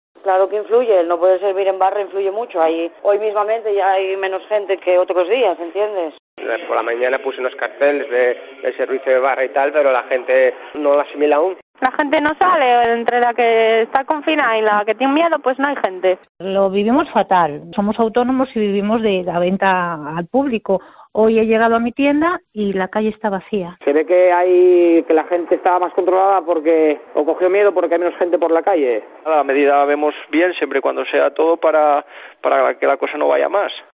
Hosteleros y comerciantes de la cuenca del Nalón hablan sobre la alerta naranja